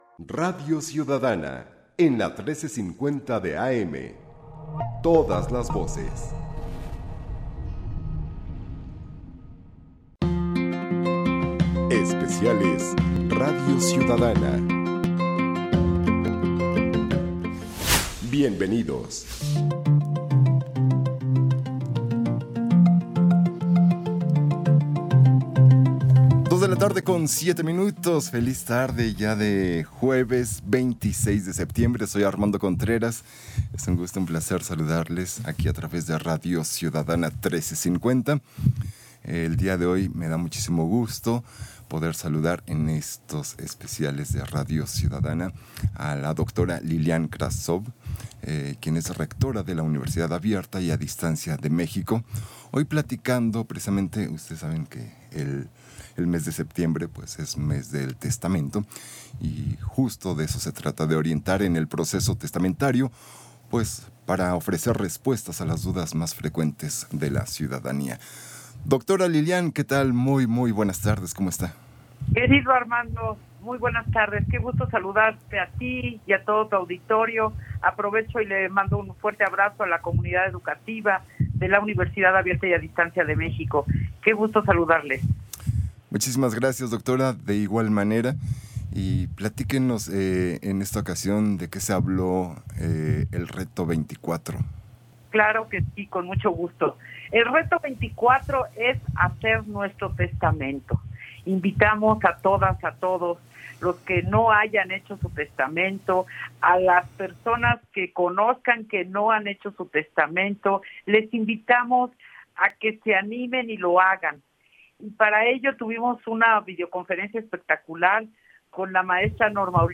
Infografía Audio Entrevista a Lilian Kravzov Appel, rectora de la UnADM, 26 de septiembre de 2024. En Radio Ciudadana 1350 AM.
entrevista_IMER_Reto_24.mp3